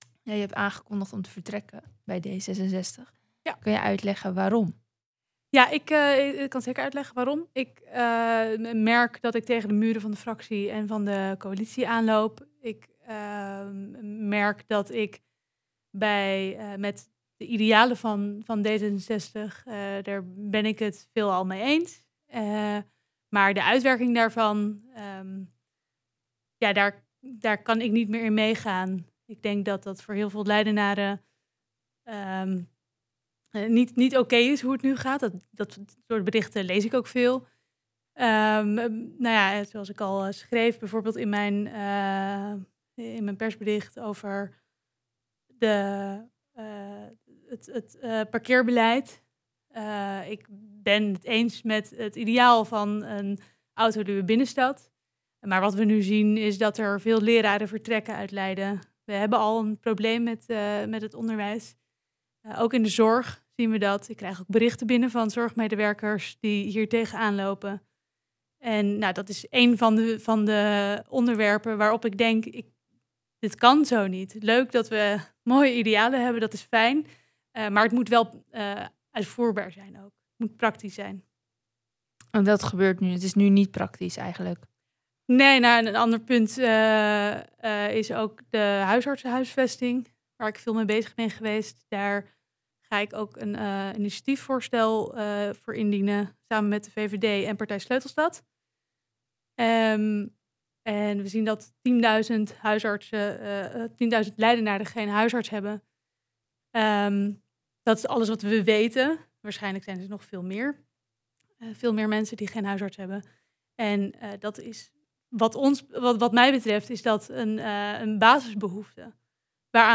Recent was Julia de Groot te gast bij Centraal+.